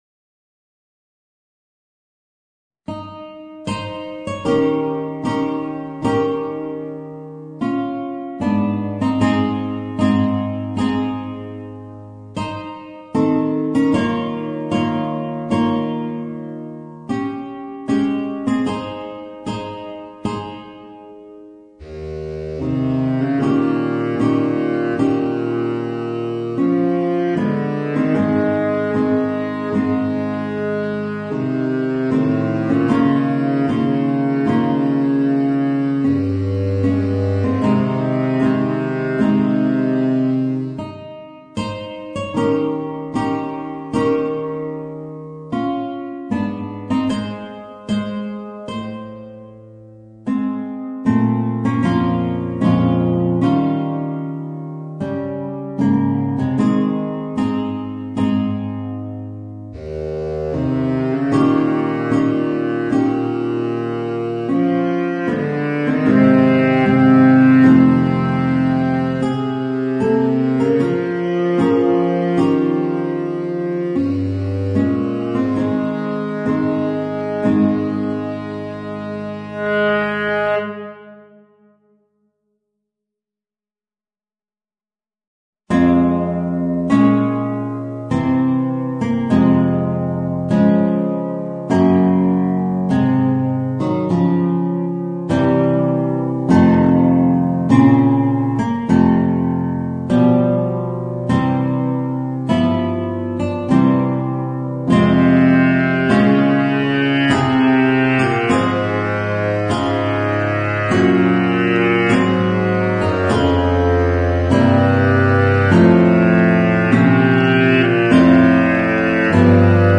Saxophone baryton & guitare